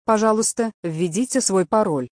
Звук женского голоса при наборе секретного кода